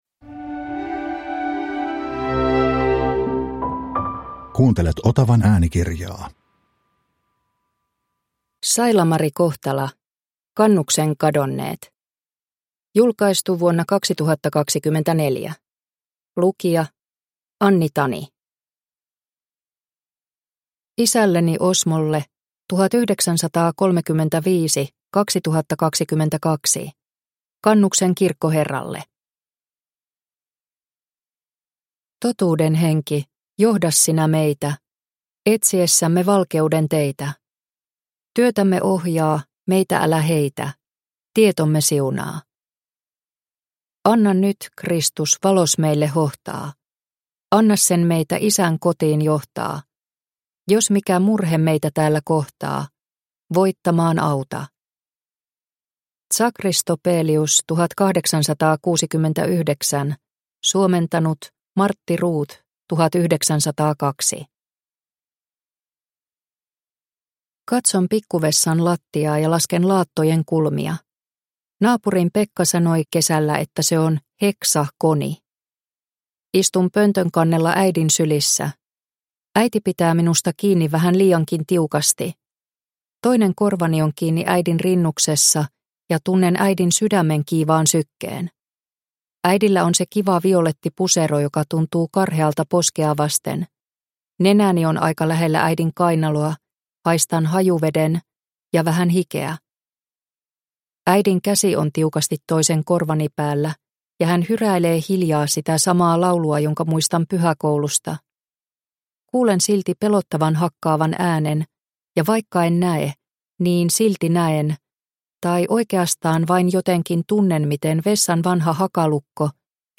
Kannuksen kadonneet – Ljudbok
Deckare & spänning Njut av en bra bok